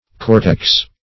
Cortex \Cor"tex\ (k[^o]r"t[e^]ks), n.; pl. Cortices (-t?-s?z).